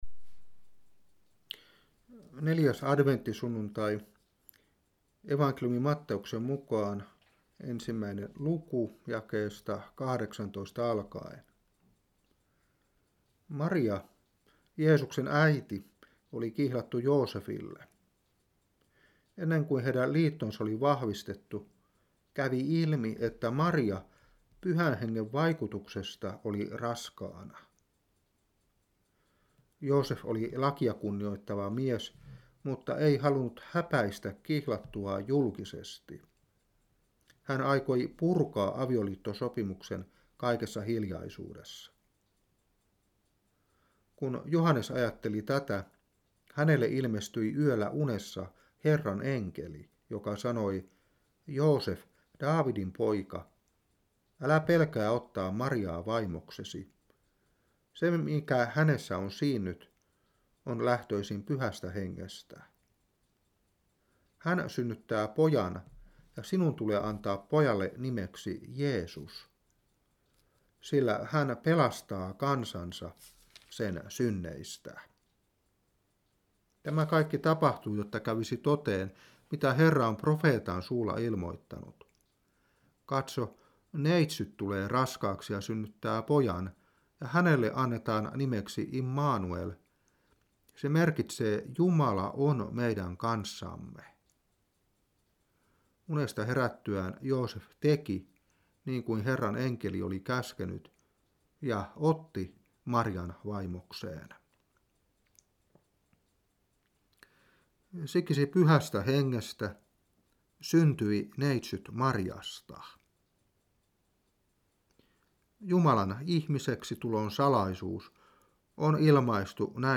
Saarna 2003-12.